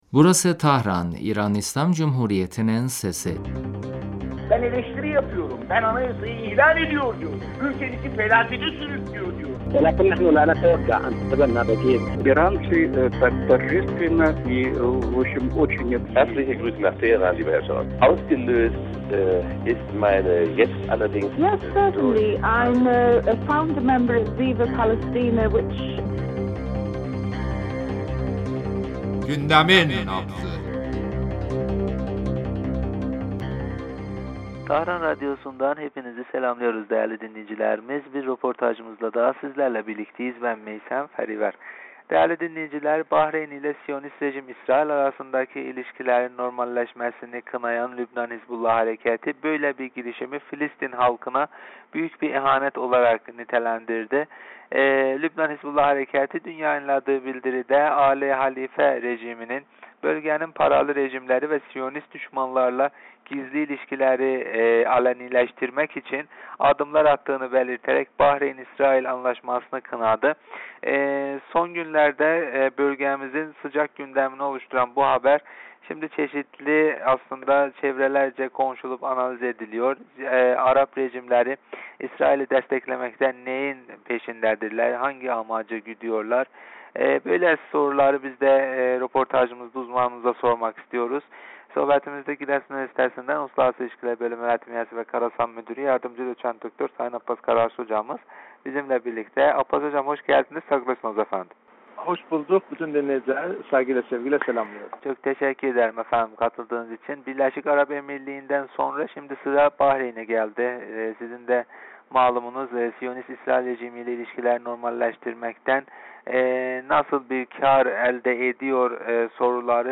telefon görüşmesinde Bahreyn'in işgal rejimi İsrail ile ilişkilerini normalleştirmesi hakkında konuştuk.